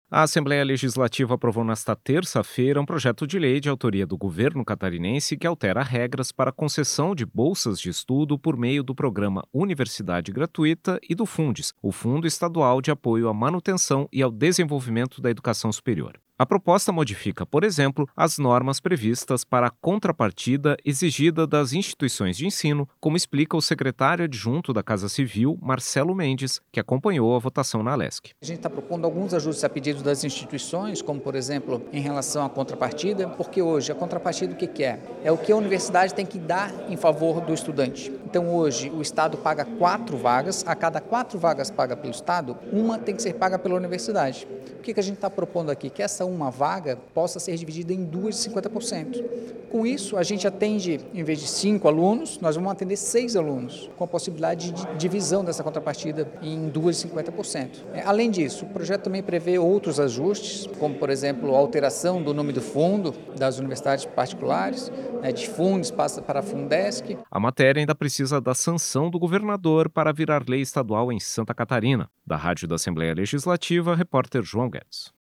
Entrevista com:
- Marcelo Mendes, secretário adjunto da Casa Civil do Estado.